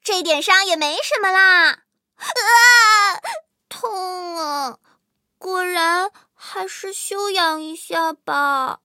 M18地狱猫中破修理语音.OGG